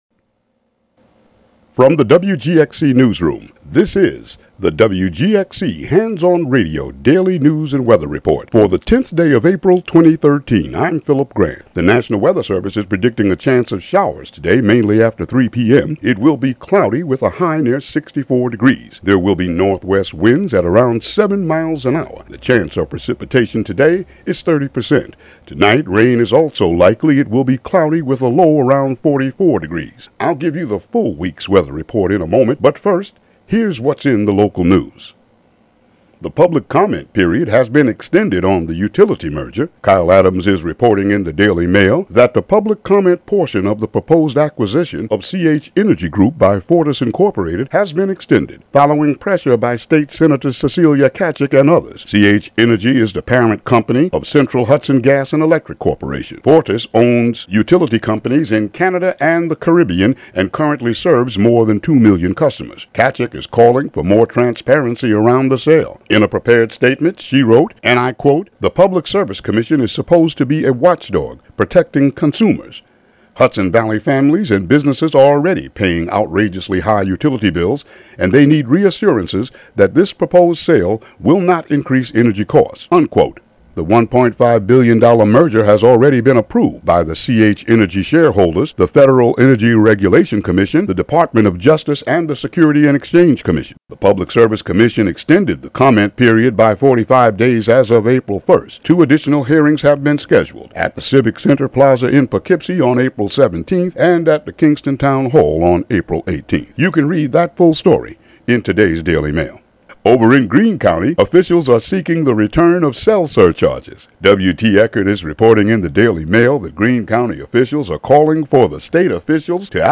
Local news headlines and weather for April 10, 2013.